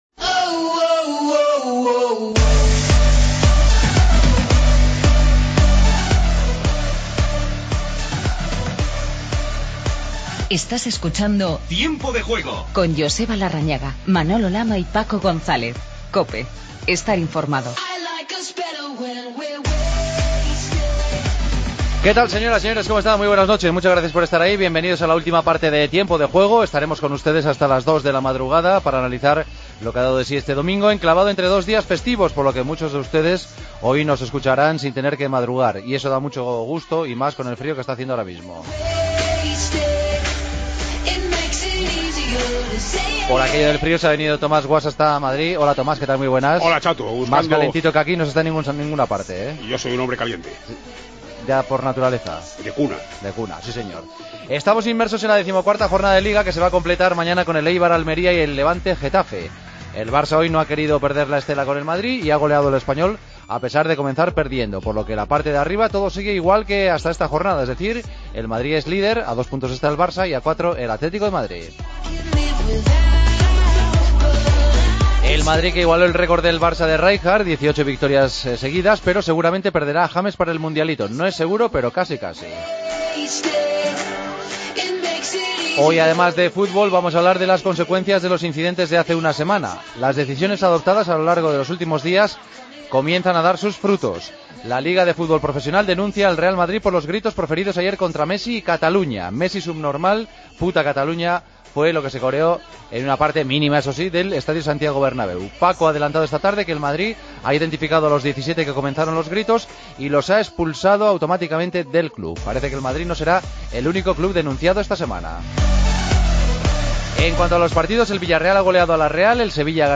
La LFP denunciará a parte del Bernabéu por insultos a Messi y a Cataluña y el Real Madrid expulsa a 17 aficionados que participaron en estos insultos. El Valencia empata 1-1 en Granada Entrevista a Negredo, que marcó. El Barcelona ganó 5-1 con tres goles de Messi.